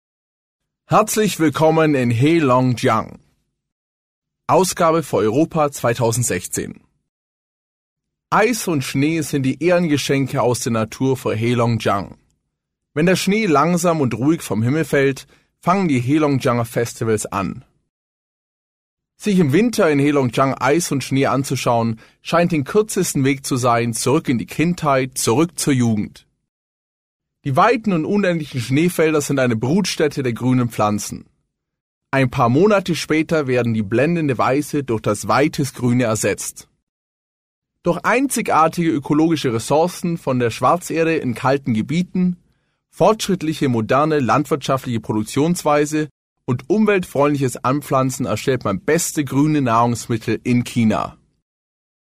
外语配音：俄语语配音 日语配音 韩语配音 法语配音 德语配音 西班牙语配音和葡萄牙语配音员及其他小语种配音演员